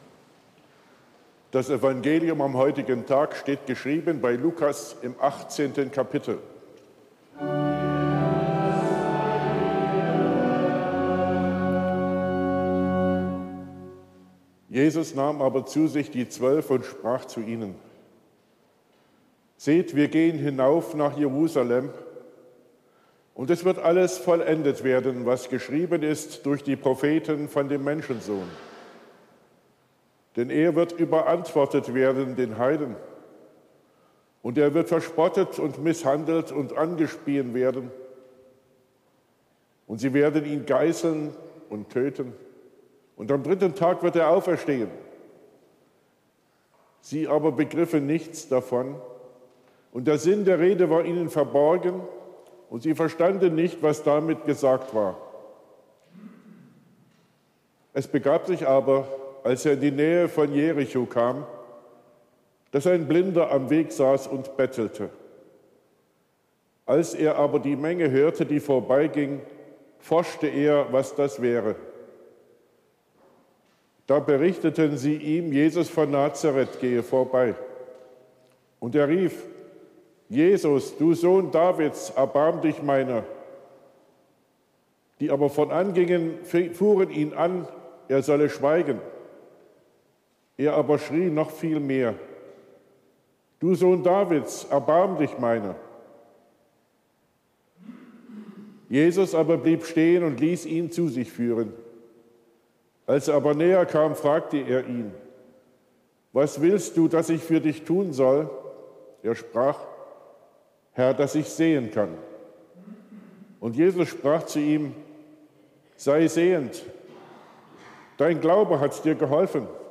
7. Lesung: Lukas 18,31-43 Evangelisch-Lutherische St. Johannesgemeinde Zwickau-Planitz
Audiomitschnitt unseres Gottesdienstes am Sonntag Estomihi 2023.